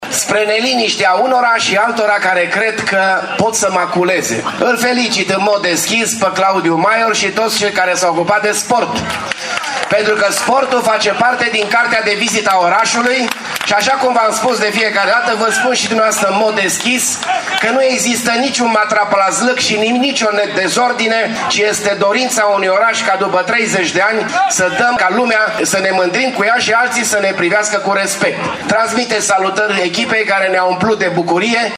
Primarul Dorin Florea a felicitat apoi echipa de fotbal a orașului, dar și pe cei care se ocupă de sportul de performanță: